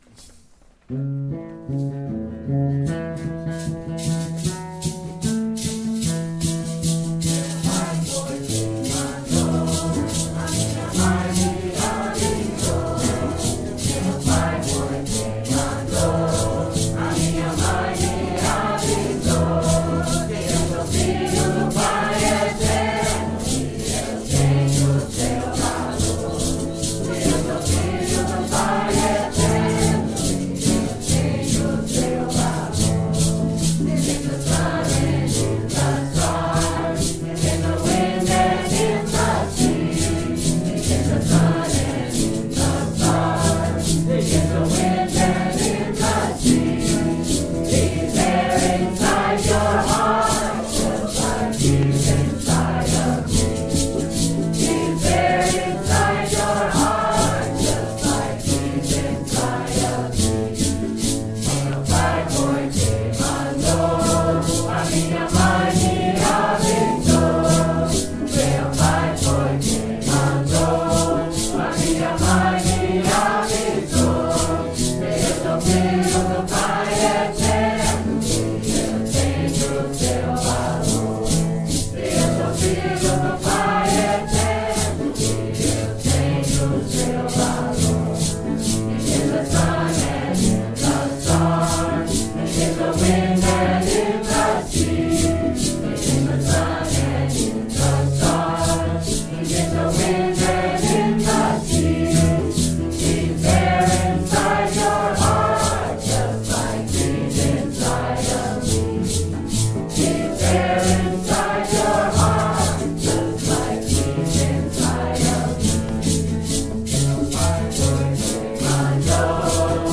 Recording source: Ashland